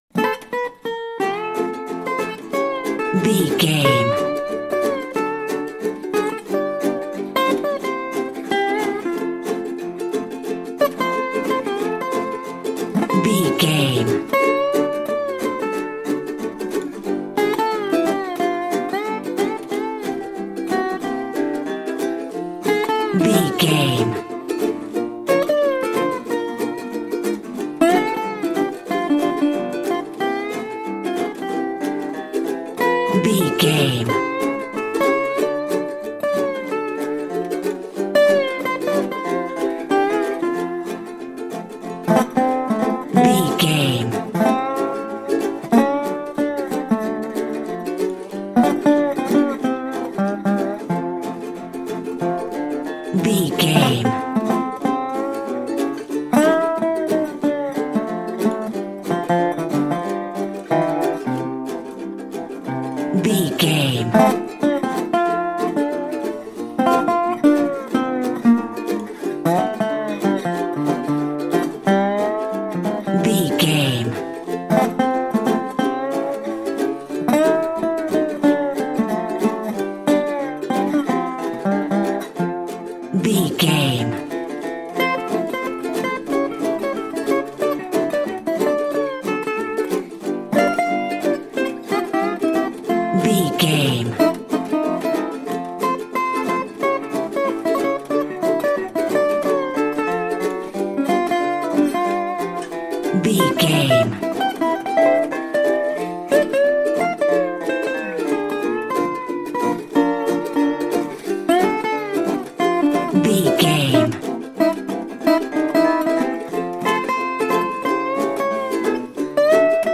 Ionian/Major
acoustic guitar
ukulele
dobro
slack key guitar